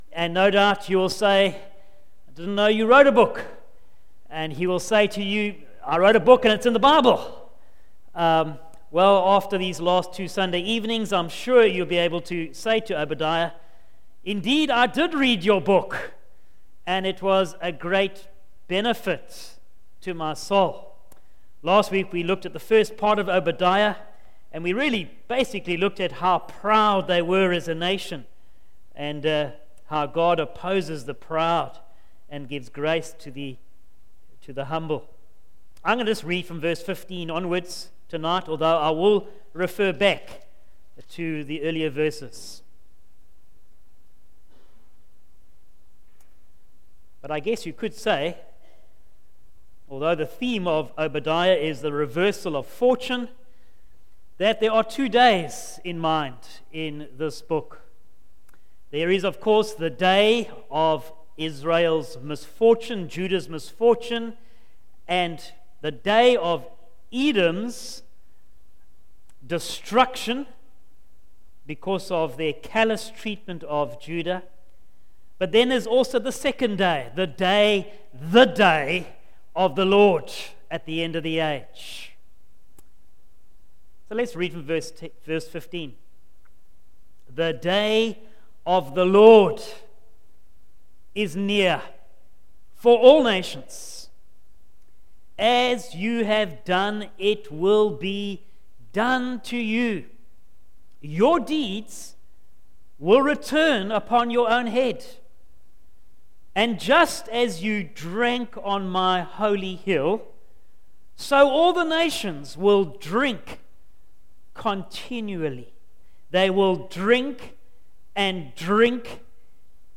Facebook Twitter email Posted in Evening Service